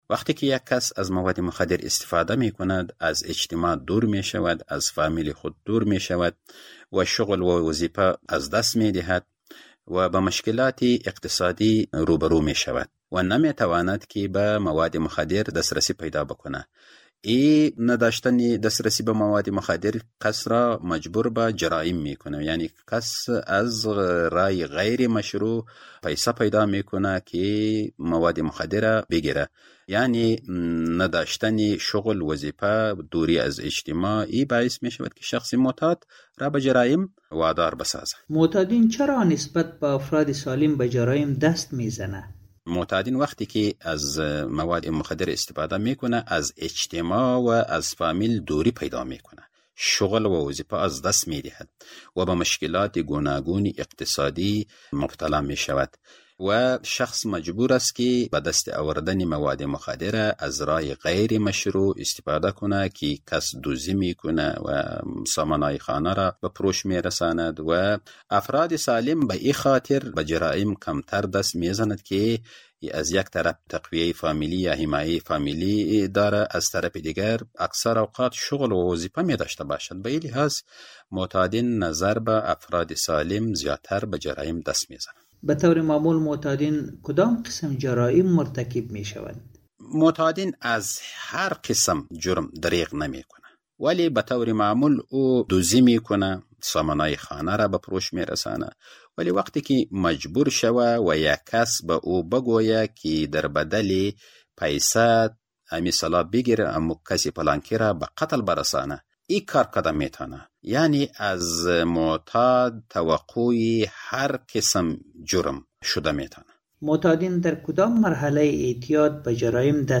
همکار ما در این مصاحبه